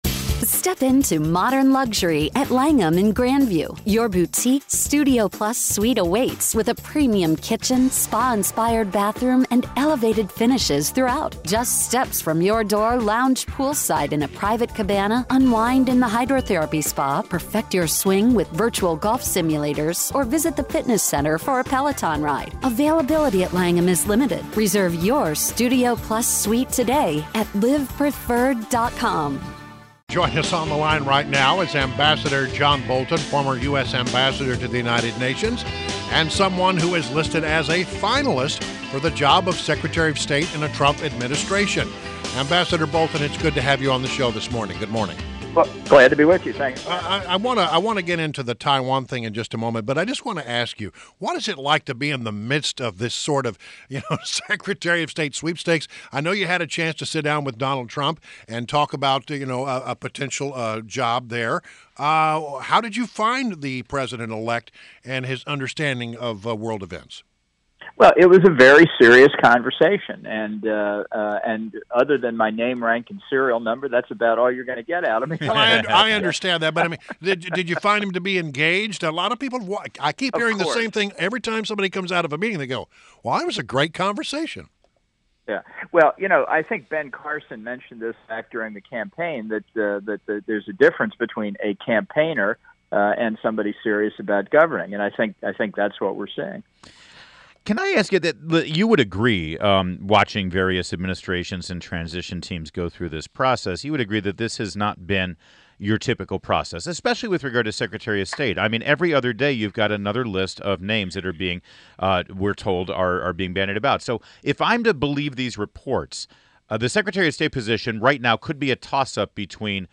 INTERVIEW — AMB. JOHN BOLTON – former U.S. Ambassador to the United Nations